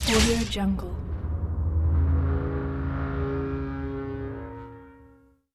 دانلود افکت صدای برخورد لیزر به فلز 9
افکت صدای برخورد لیزر به فلز 9 یک گزینه عالی برای هر پروژه ای است که به صداهای صنعتی و جنبه های دیگر مانند سوزاندن، متالیک و ضربه نیاز دارد.
Sample rate 16-Bit Stereo, 44.1 kHz